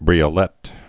(brēə-lĕt)